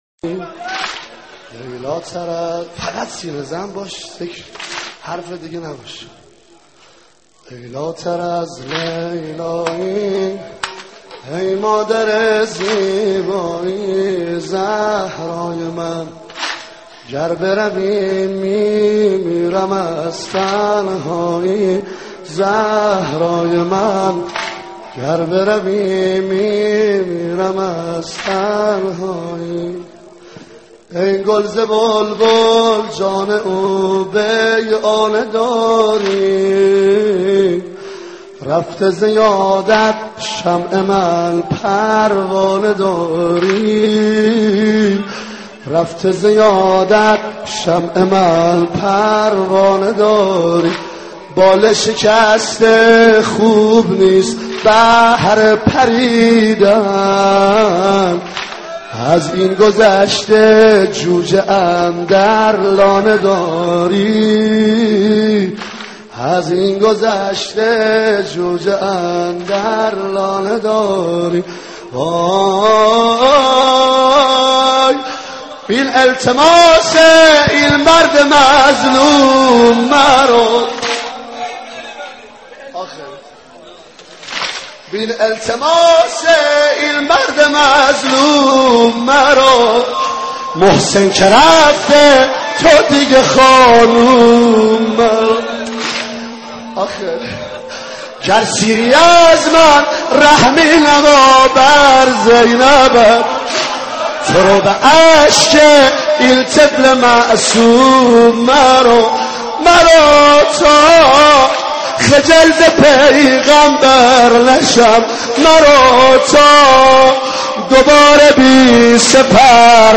نوحه‌سرایی